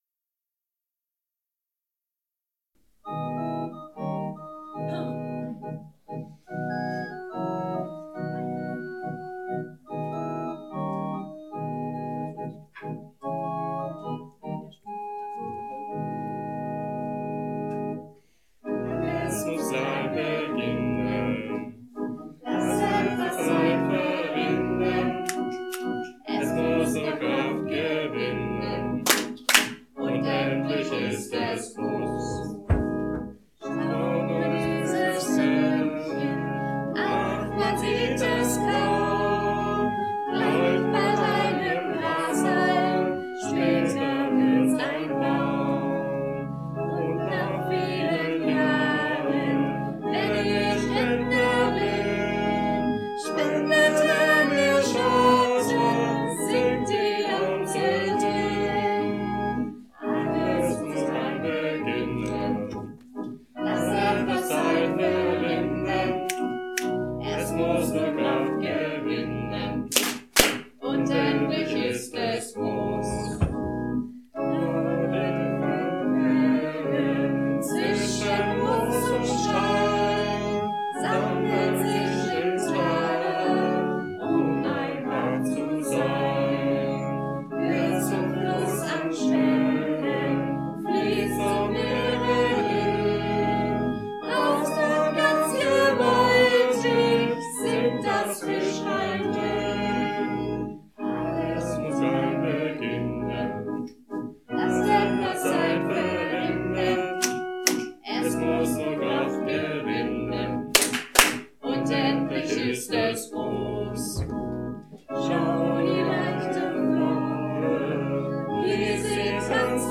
Frankfurt-Unterliederbach - Predigten zum Herunterladen